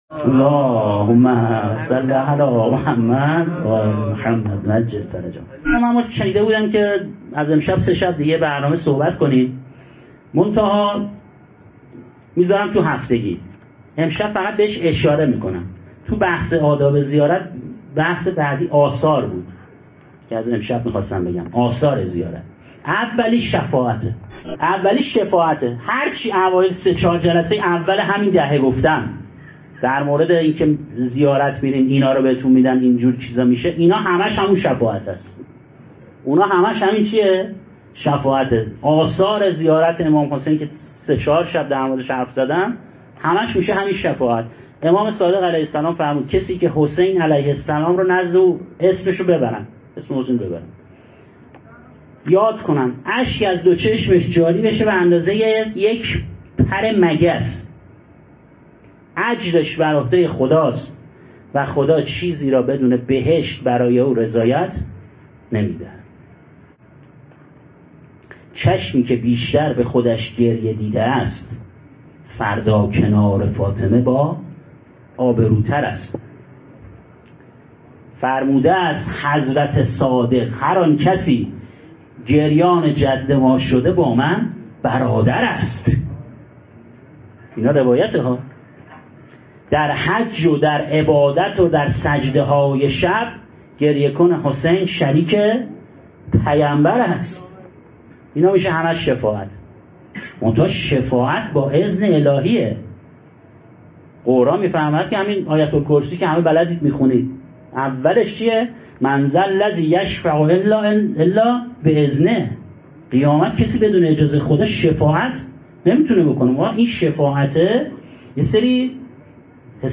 شام غریبان محرم 1400 ـ هیأت متوسلین به جواد الائمه علیه السلام